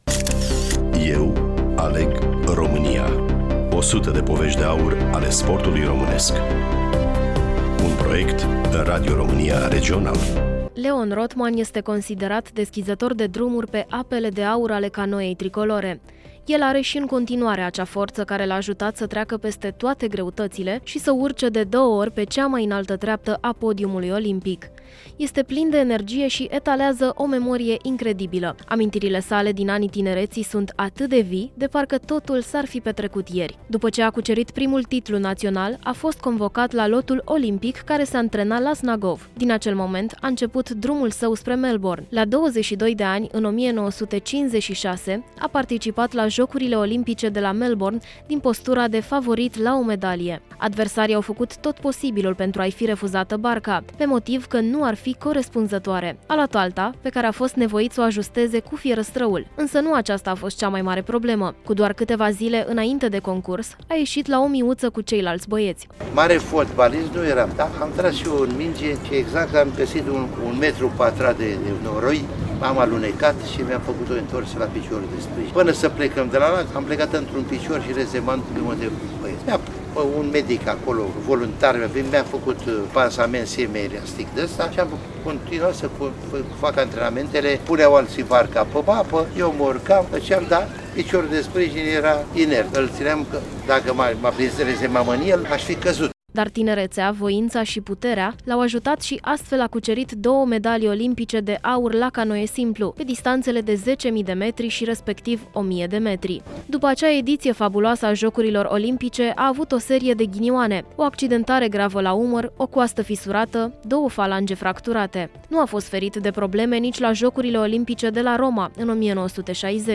Prezentator
Voice over